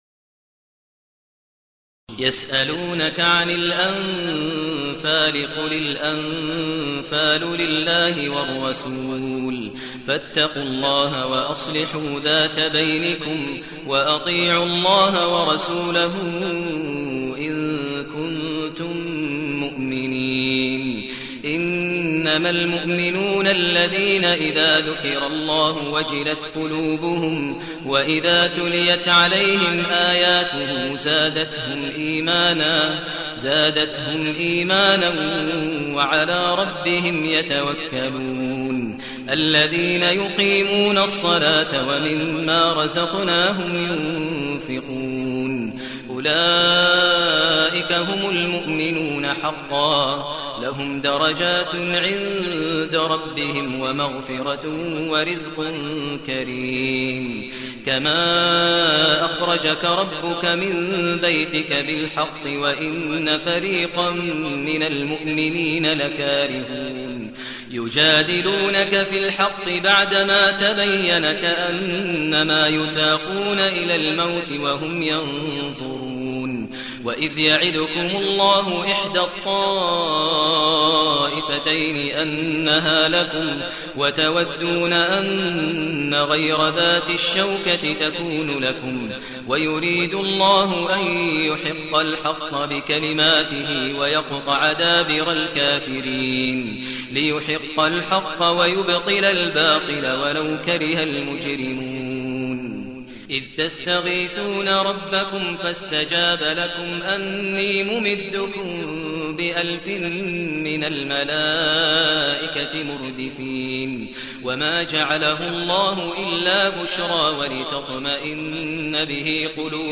المكان: المسجد النبوي الشيخ: فضيلة الشيخ ماهر المعيقلي فضيلة الشيخ ماهر المعيقلي الأنفال